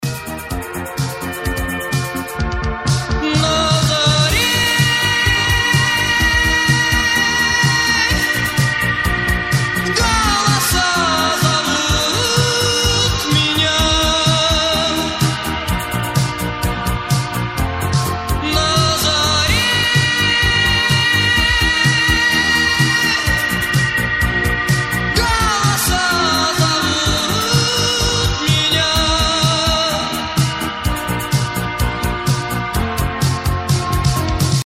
• Качество: 192, Stereo
мужской вокал
Synth Pop
80-е
new wave